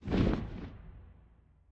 assets / minecraft / sounds / mob / phantom / flap5.ogg
flap5.ogg